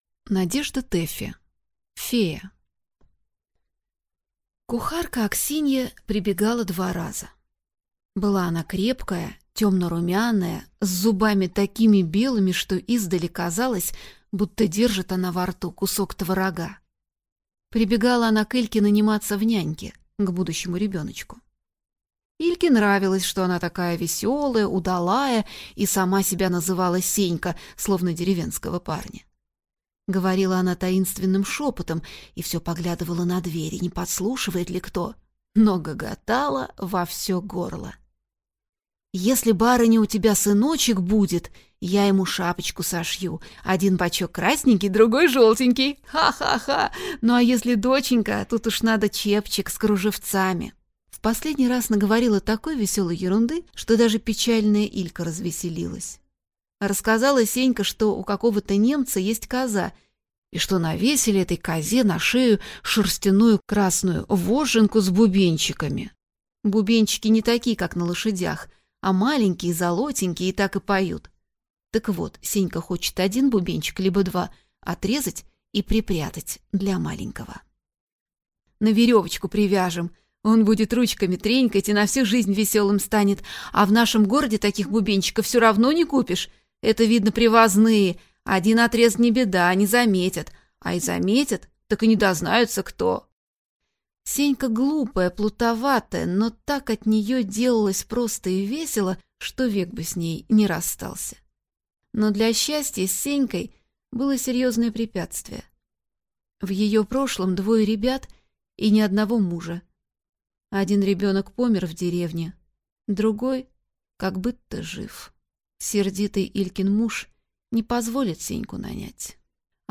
Aудиокнига Фея